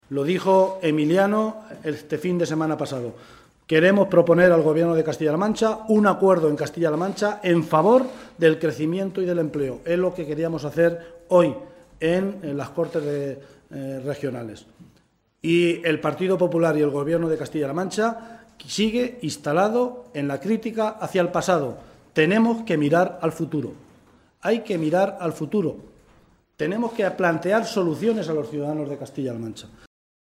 El portavoz parlamentario del PSOE en las Cortes de Castilla-La Mancha, José Luis Martínez Guijarro, ha comparecido ante los medios en la Cámara Regional para valorar la intervención de la Presidenta de Castilla-La Mancha, ante el Pleno durante el debate sobre la evolución del desempleo en nuestra región.
Cortes de audio de la rueda de prensa